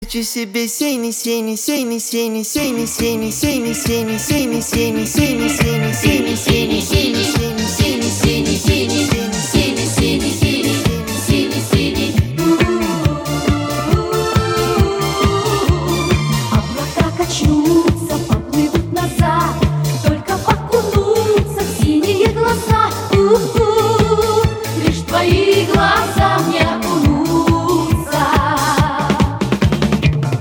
• Качество: 251, Stereo